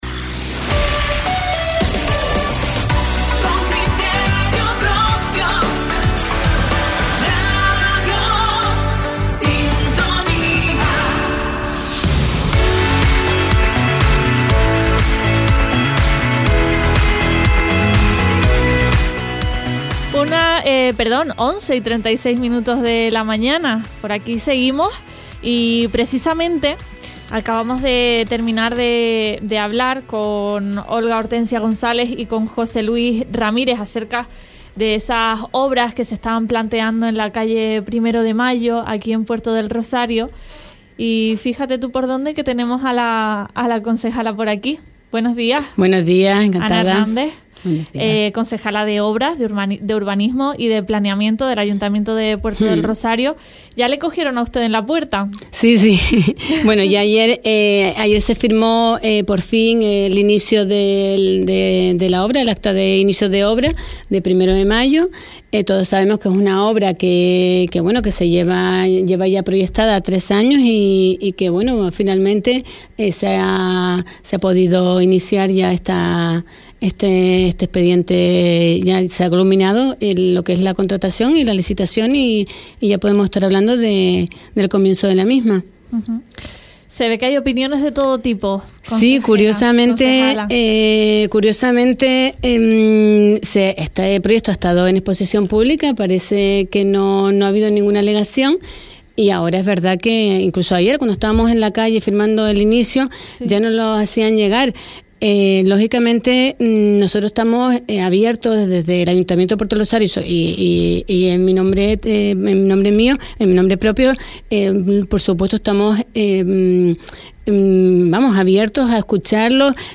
Acerca de las obras de remodelación de la calle Primero de Mayo, en Puerto del Rosario, hemos conversado con la concejala Ana Hernández